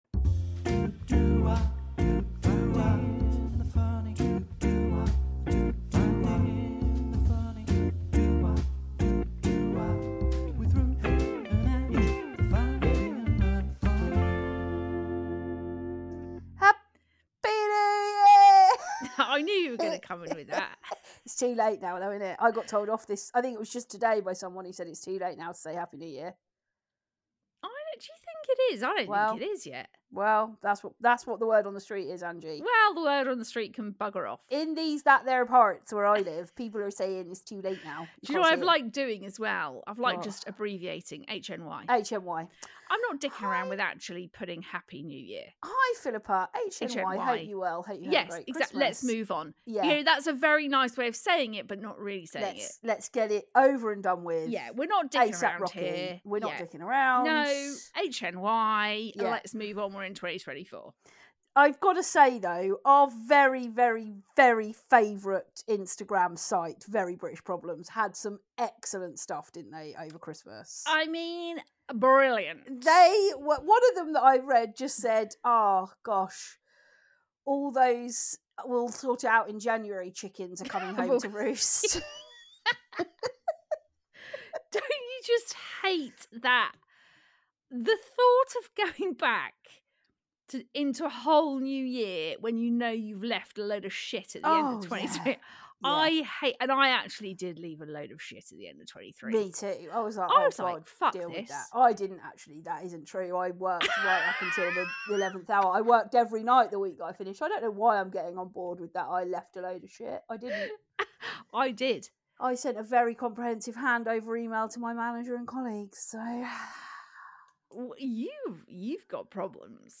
This weeks episode is full of the chaotic laughter we have come to love on FTF.